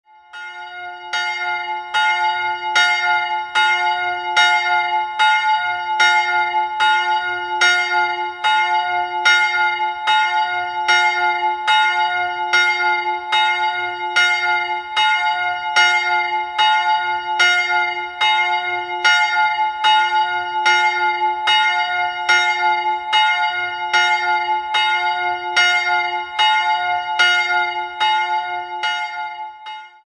Einzelglocke: fis''
Sie wiegt 138 kg, erklingt im Ton fis''-7 und hat einen Durchmesser von 582 mm.
bell
Die Glocke der Klosterkirche ist auf das Geläut der Stadtpfarrkirche abgestimmt.